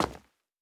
added stepping sounds
PavementTiles_Mono_04.wav